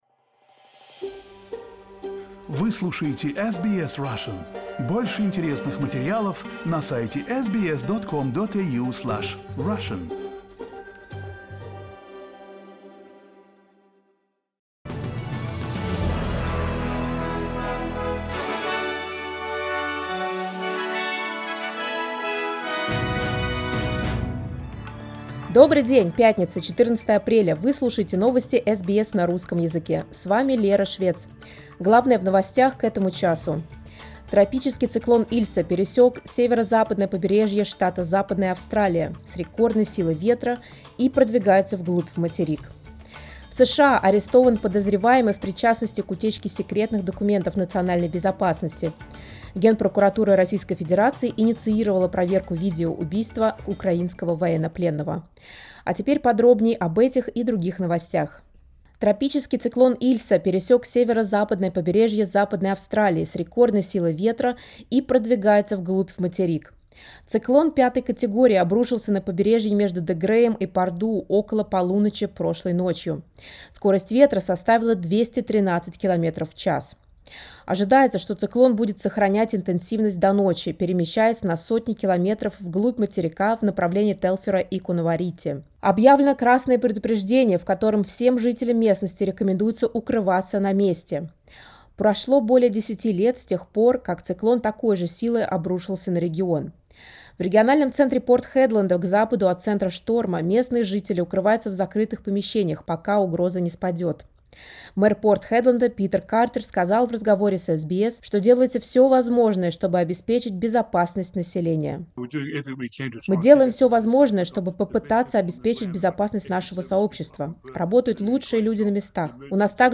SBS news in Russian — 14.04.2023